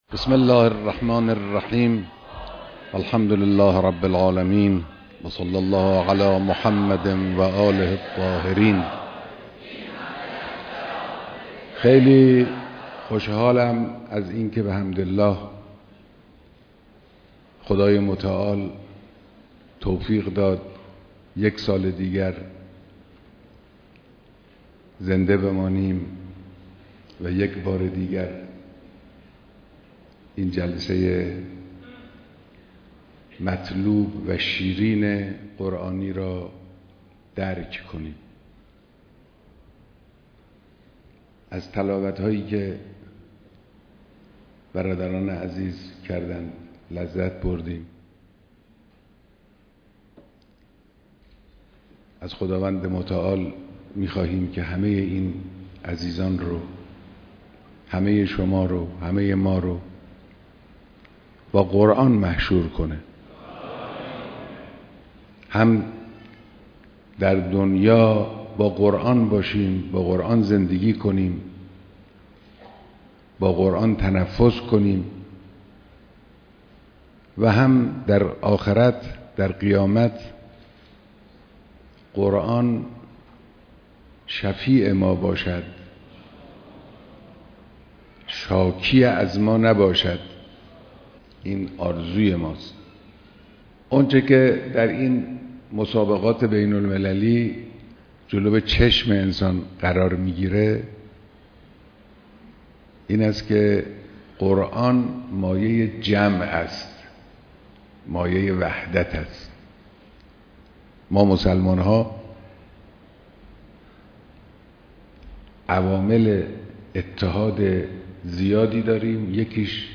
بيانات در ديدار شركت‌كنندگان در مسابقات قرآن‌